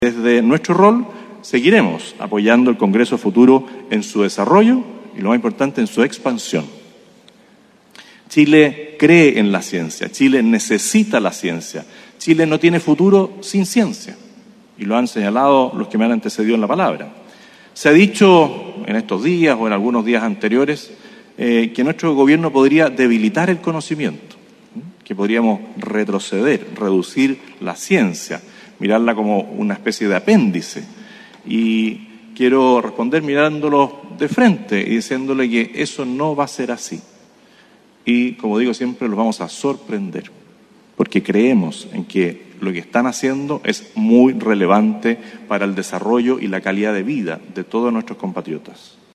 La inauguración del Congreso Futuro contó con la participación del Presidente Gabriel Boric y del Presidente Electo José Antonio Kast, entre otras autoridades.